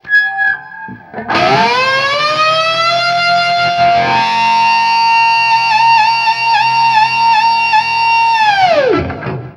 DIVEBOMB10-R.wav